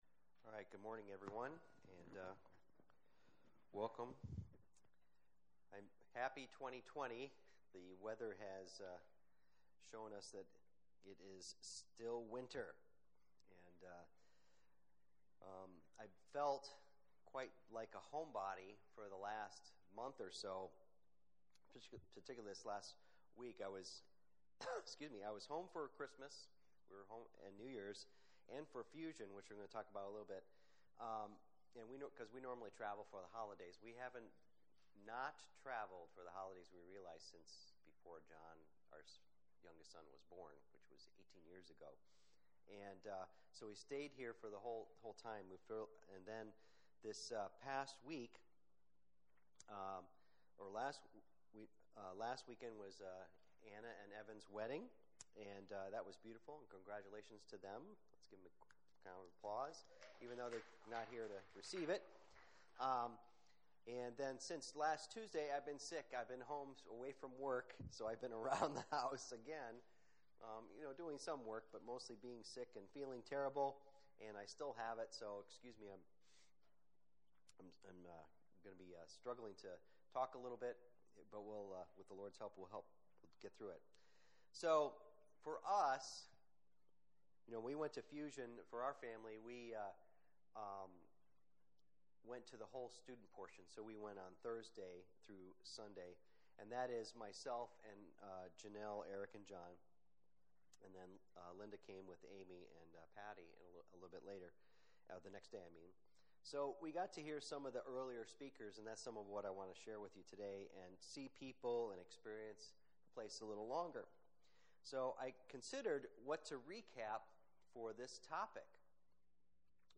Fusion Conference Service Type: Sunday Morning %todo_render% « Fusion Conference 2020 Take Away Pt 1 God is doing something new in 2020!